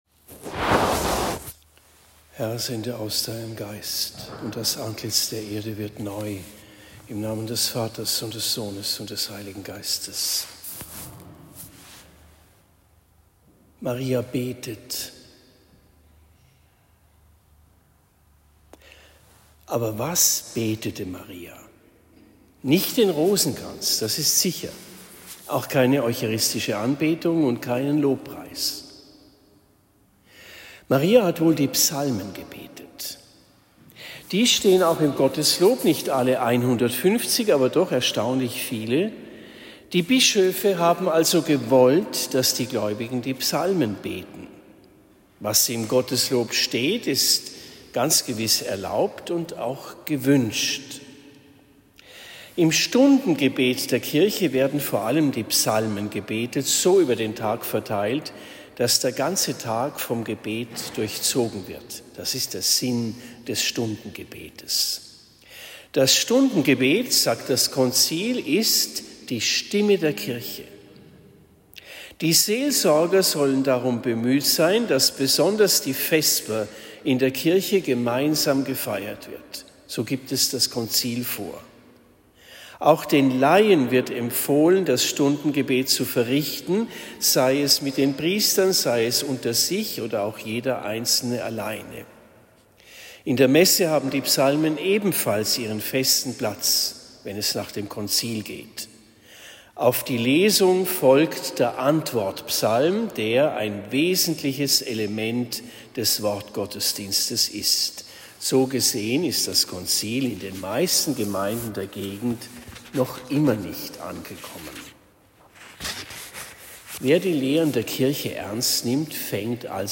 Predigt in Marktheidenfeld St.-Laurentius am 16. Mai 2025